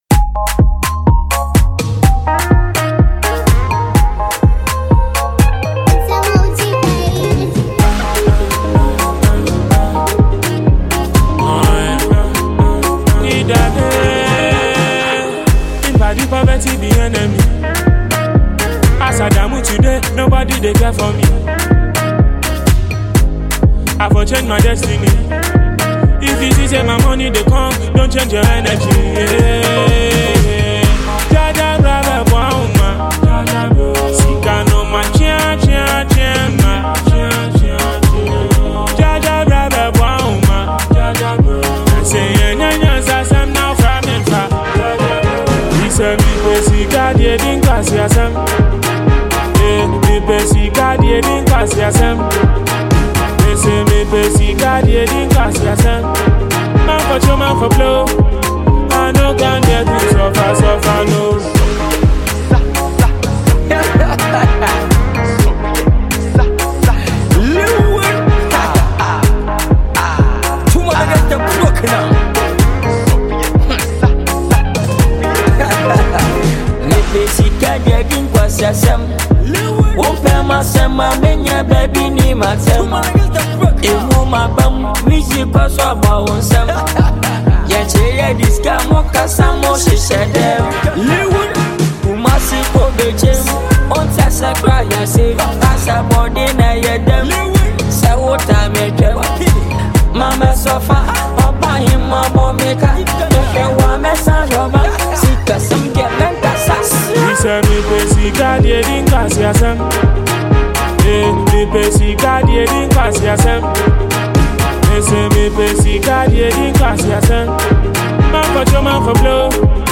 Ghana MusicMusic
Highlife
danceable jam